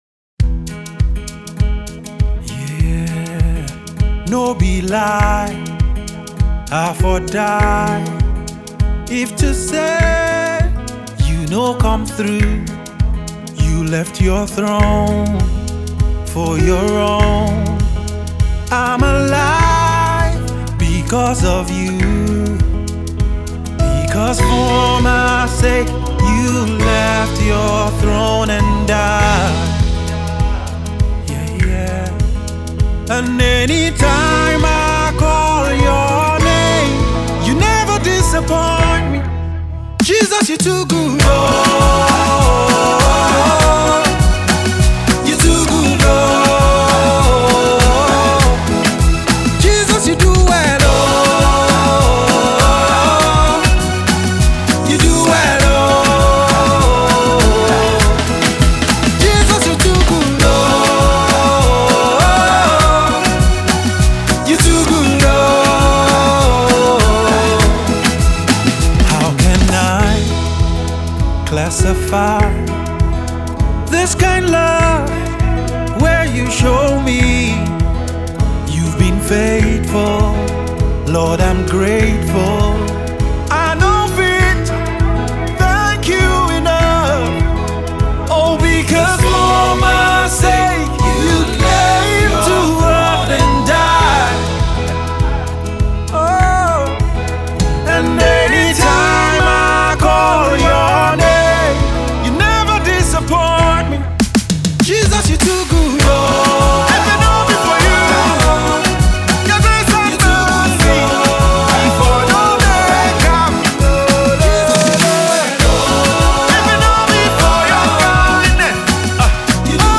endlessly-playable and well-rounded song of thanksgiving